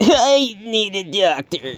Category 🗣 Voices
cough funny funny-voice hurt injured male man pain sound effect free sound royalty free Voices